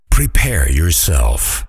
prepare_yourself.ogg